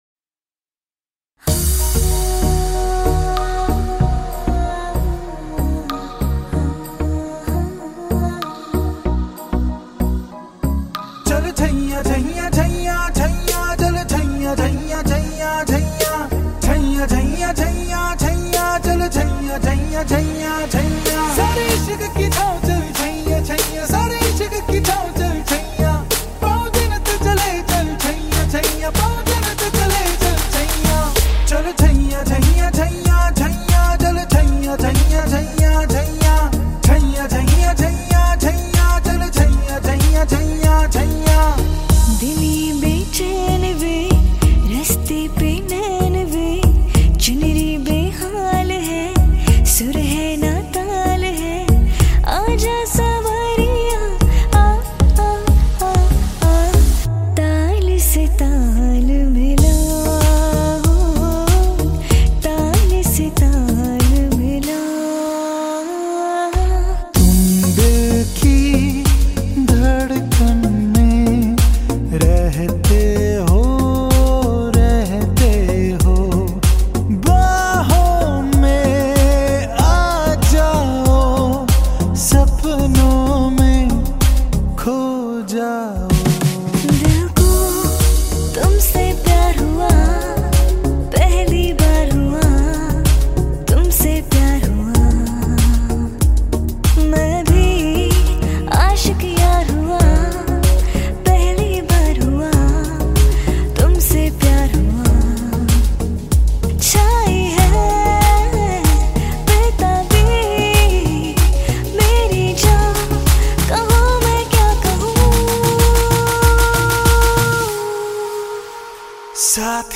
COVER SONG
Vocals